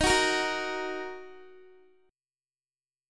D#m Chord (page 3)
Listen to D#m strummed